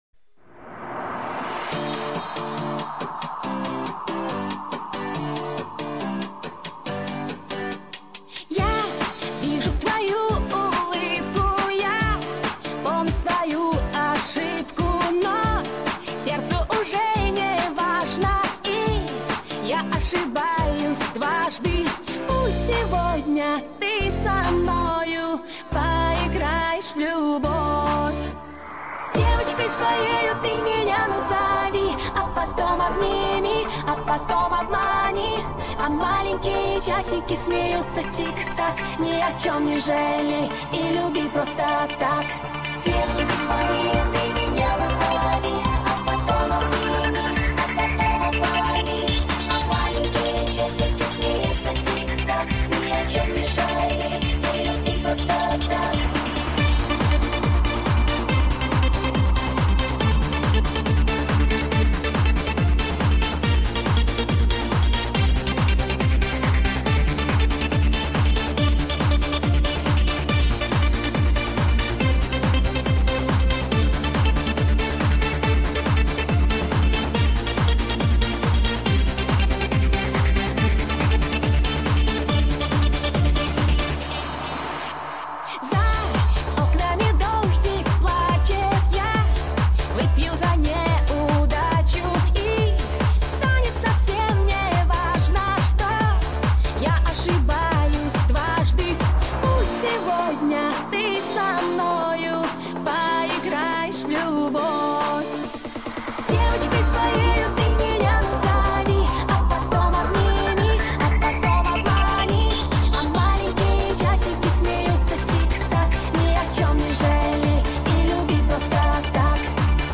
她的嗓音通透、明亮，音域宽，歌唱技巧娴熟自如， 不同风格的歌曲经她的演绎都会增色十分。